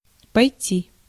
Ääntäminen
US : IPA : [ˈfəŋ(k).ʃən] UK : IPA : /ˈfʌŋ(k)ʃən/ IPA : /ˈfʌŋkʃn̩/ US : IPA : /ˈfʌŋkʃən/ IPA : [ˈfʌŋkʃɪ̈n] IPA : [ˈfʌŋkʃn̩]